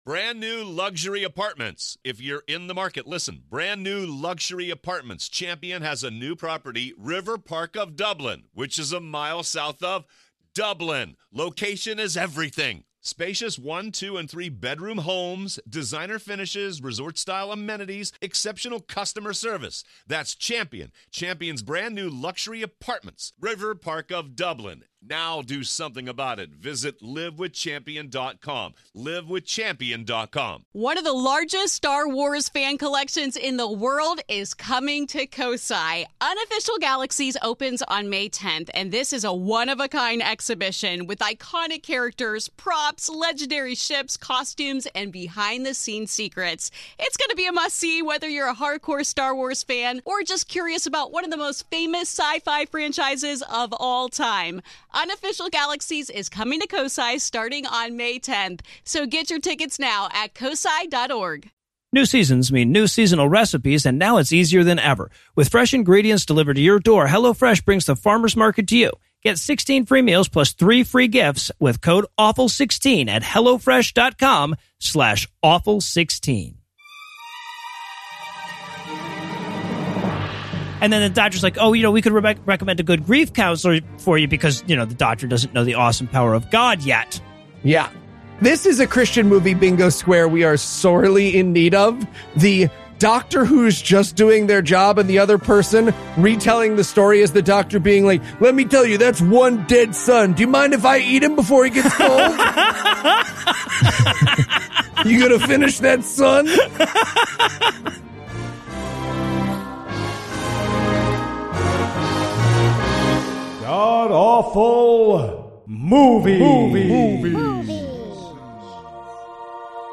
This week, the guys team up for an atheist review of Father Stu, the story of a guy who never had anything particularly interesting happen to him, decided to become a priest, then died.